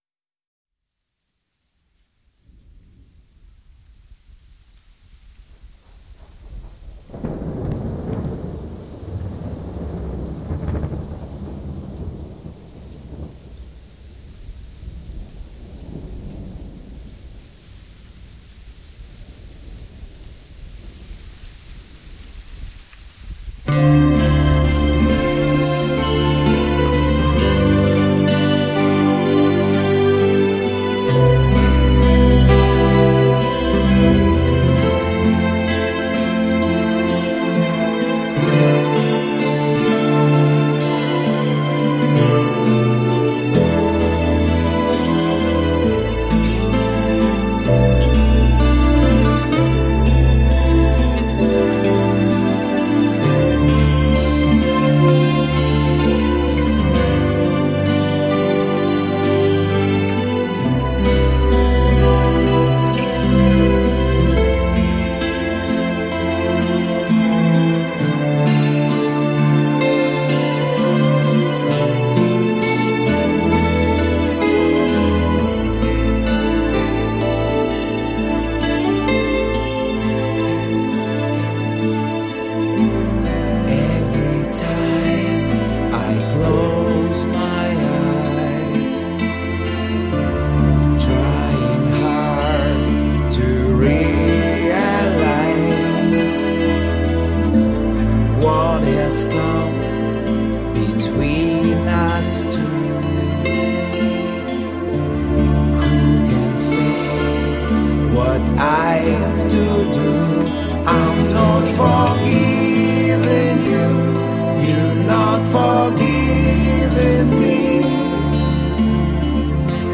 Стилистически музыка ближе к блюз-року. Тексты англоязычные.